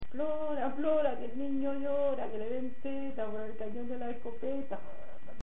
Materia / geográfico / evento: Canciones de la Candelaria Icono con lupa
Ácula (Ventas de Huelma, Granada. Pedanía) Icono con lupa
Secciones - Biblioteca de Voces - Cultura oral